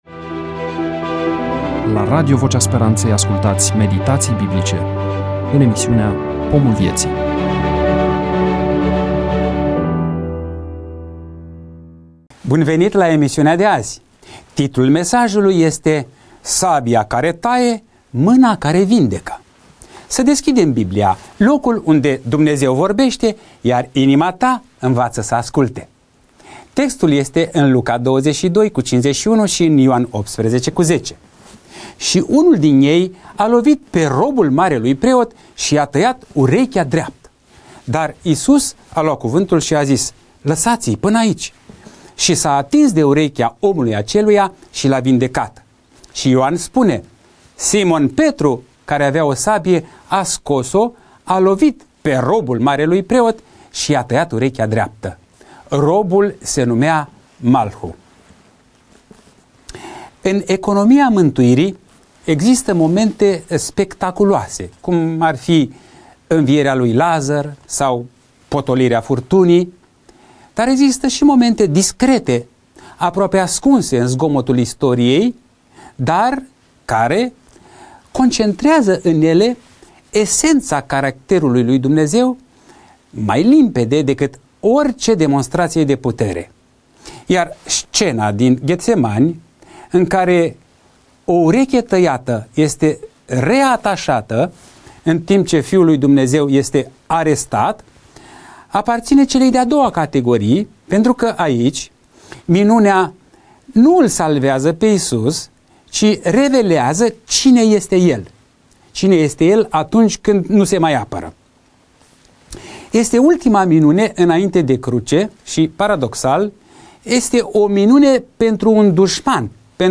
EMISIUNEA: Predică DATA INREGISTRARII: 20.03.2026 VIZUALIZARI: 37